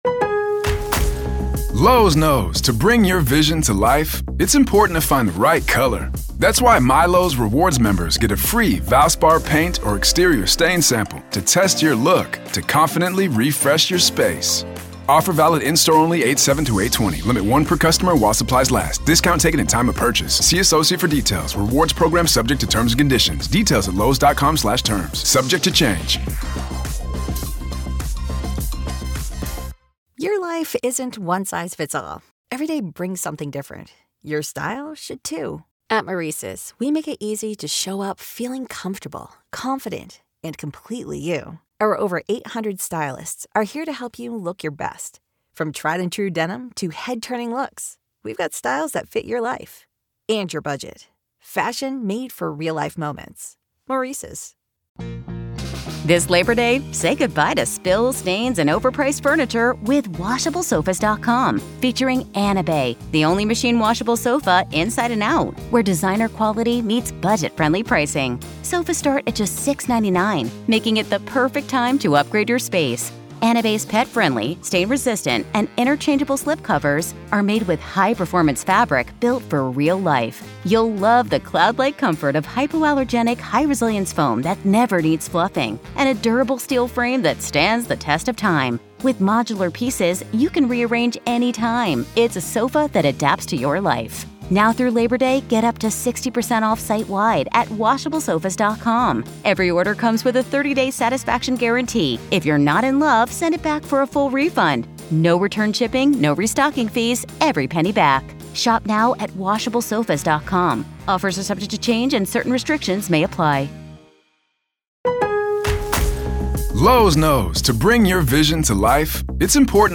In today's exclusive interview